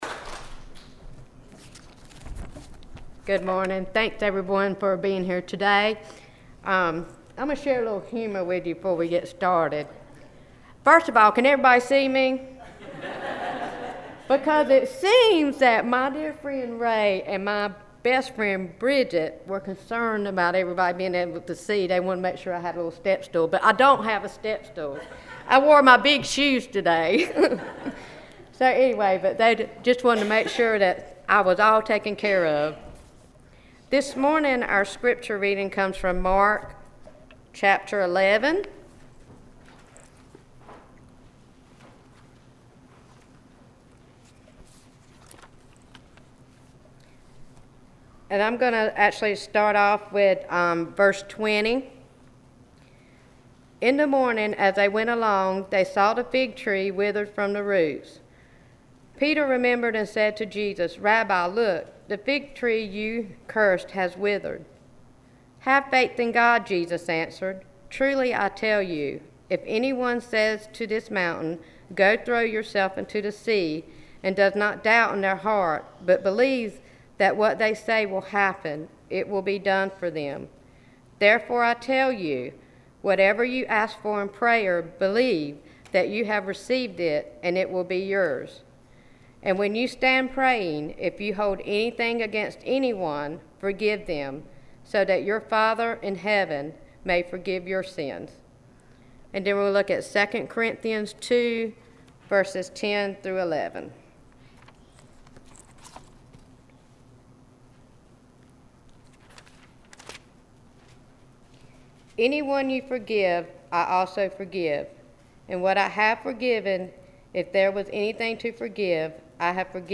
Sermon
7-19-scripture-and-sermon.mp3